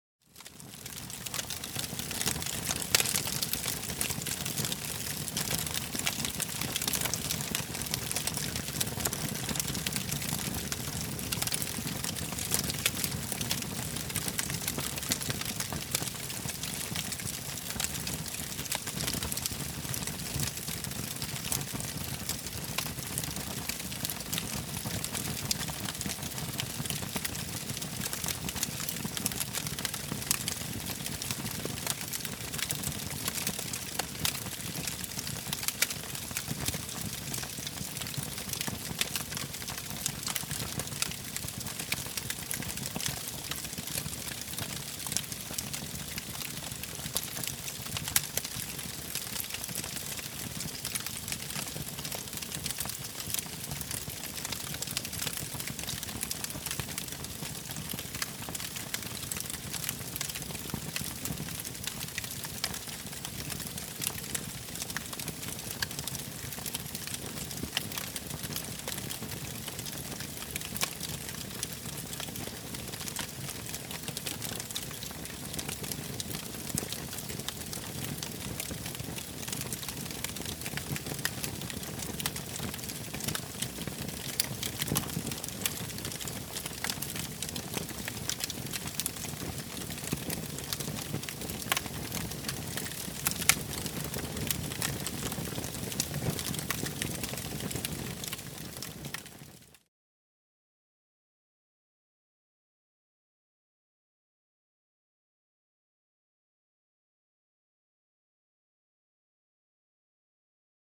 Âm thanh tiếng Đốt cháy Cây củi, Lá cây…